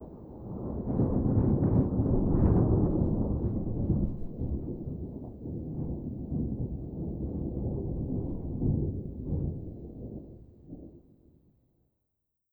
tenkoku_thunder_distant05.wav